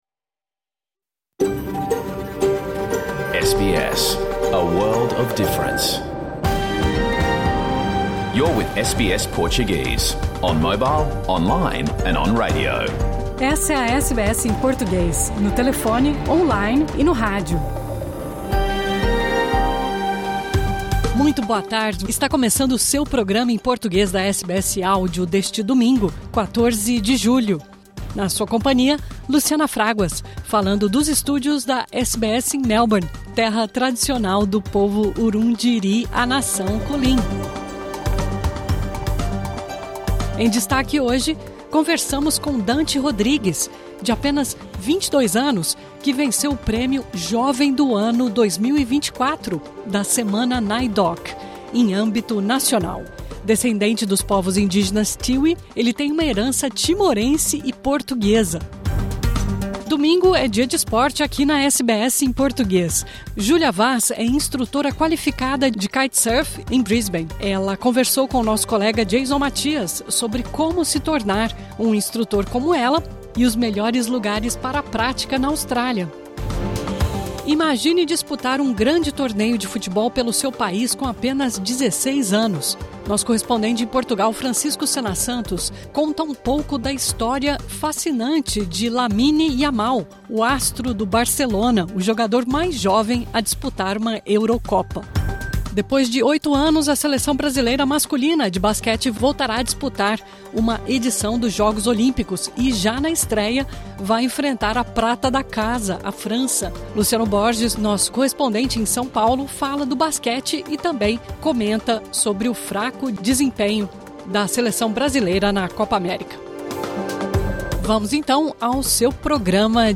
Programa ao vivo | Domingo 14 de julho